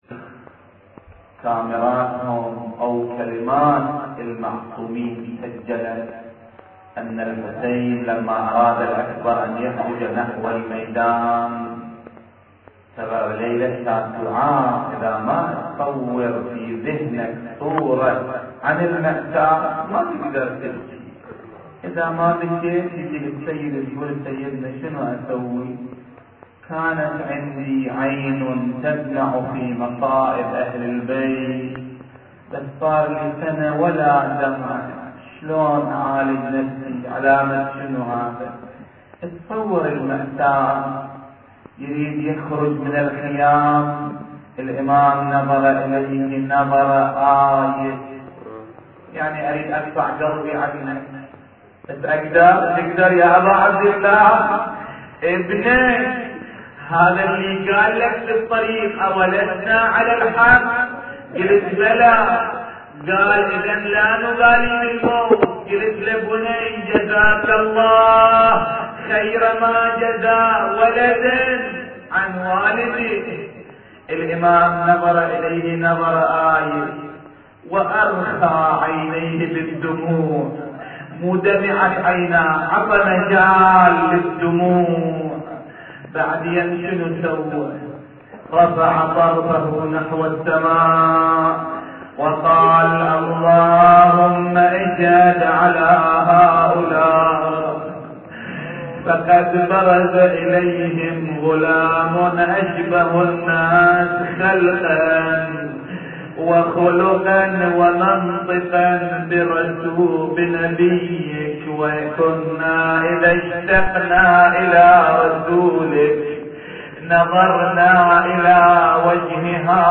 نواعي ليلة تاسع محرم 1430هـ